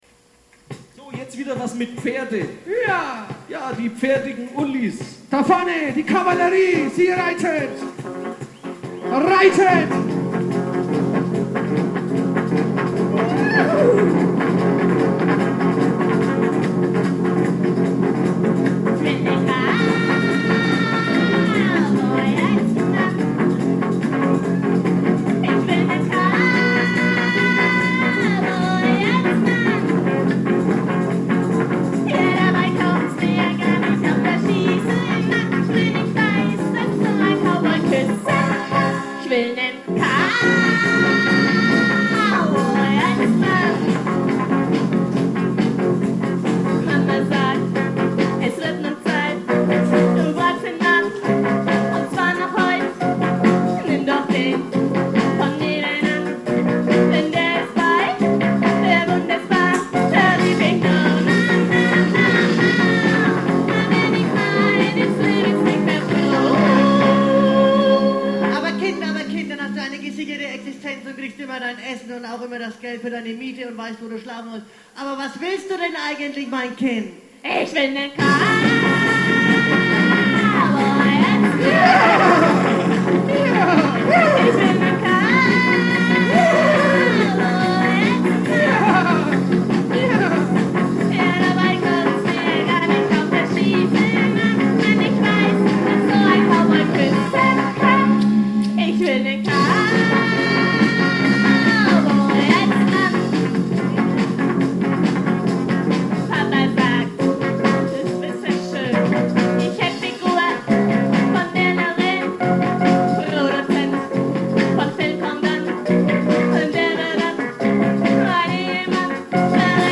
einen Schlager